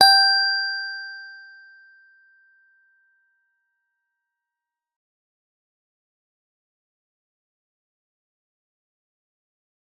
G_Musicbox-G5-f.wav